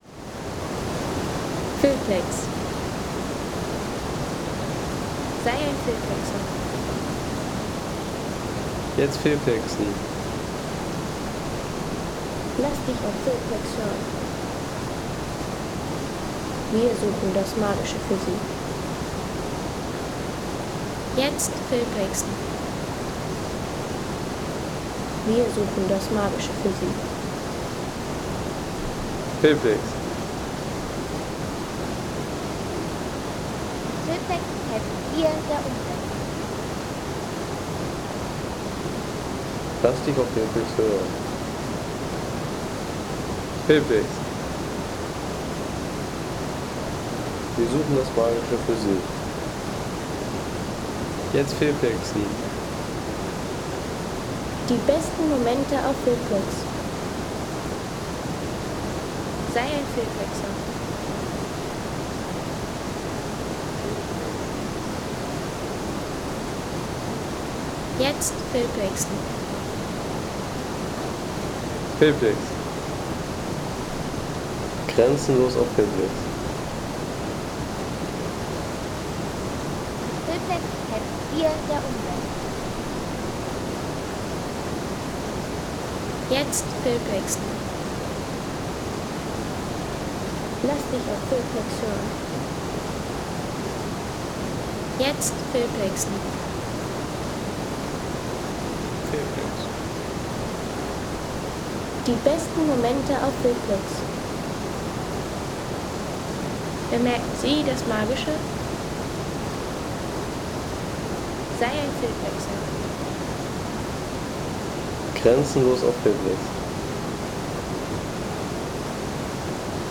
Wasserbach Windache Soundeffekt für Film und Natur
Wasserbach Windache | Authentische alpine Bachatmosphäre
Authentische Gebirgsbach-Atmosphäre der Windache bei Sölden.
Bringe den lebendigen Klang strömenden Bergwassers in Filme, Outdoor-Szenen, Reiseclips und kreative Audioprojekte.